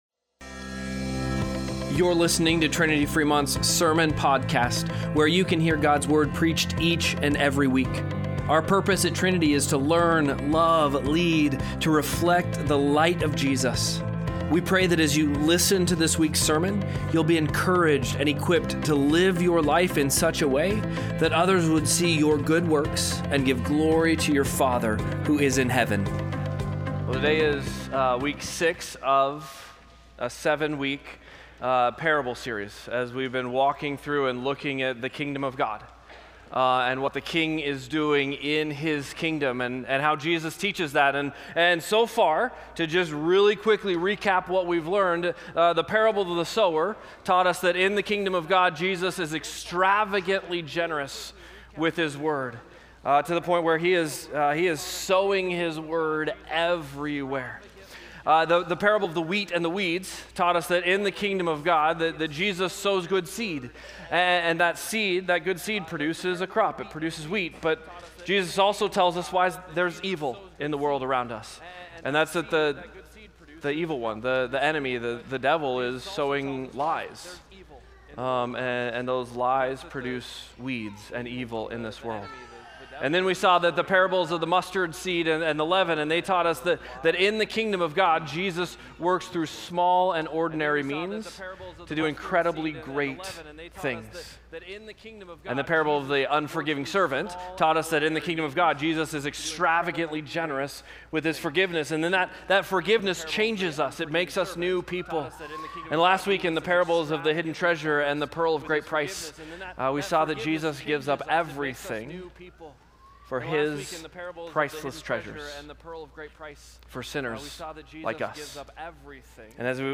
Sermon-Podcast-02-08.mp3